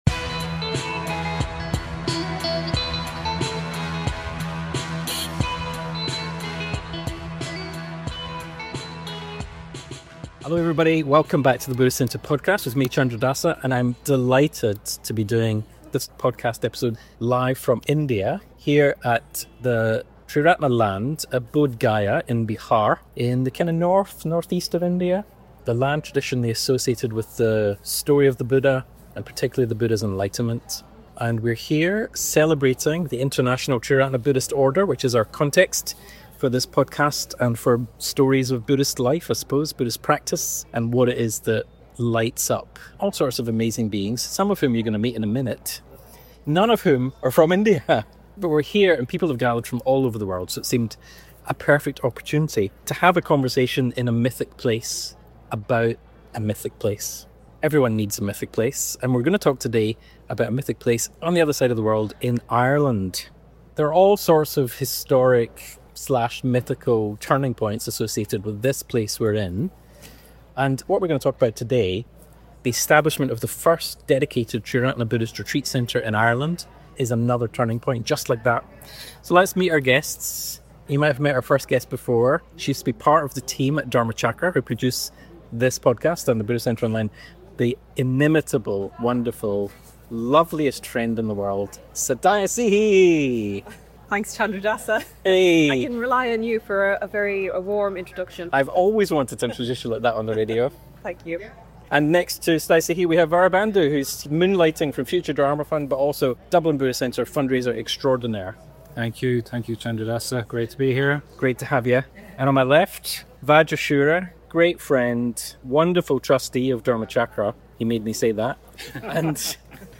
Not far from the bodhi tree, where the Buddha’s great achievement is said to have taken place, you’ll find us deep in conversation with visitiing leaders from the Dublin Buddhist Centre about their vision of building a Buddhist retreat centre in Eire: a realm of beauty and a fitting home for distinctively Irish Dharma practice in the heart of County Clare.
As a bonus, we close this conversation with a recitation of the traditional Buddhist ethical precepts rendered into Gaelic.